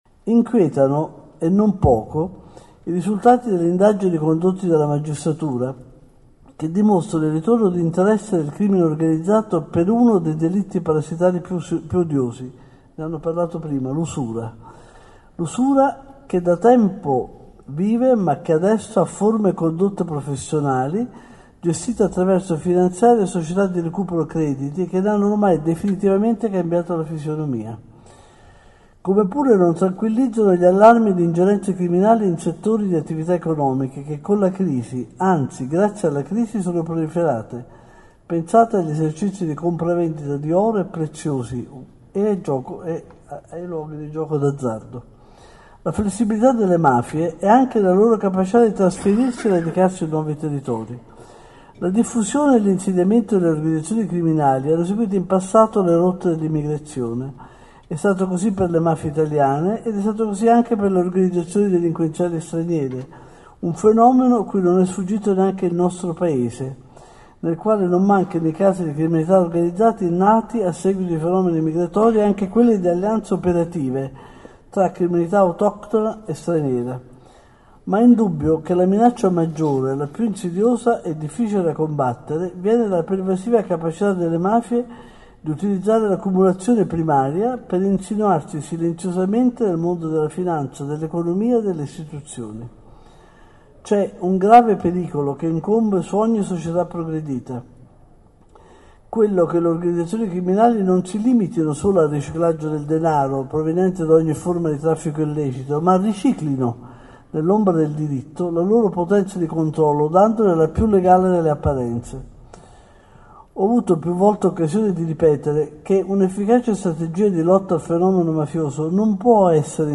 E’ la strategia indicata dal ministro della Giustizia Anna Maria Cancellieri, oggi intervenuta a Bologna a un convegno sulle mafie promosso dall’europarlamentare Salvatore Caronna. Il guardasigilli ha espresso una particolare preoccupazione per il fenomeno dell’usura.